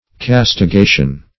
Castigation \Cas`ti*ga"tion\, n. [L. catigatio.]